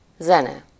Ääntäminen
UK : IPA : /ˈmjuːzɪk/ US : IPA : /ˈmjuzɪk/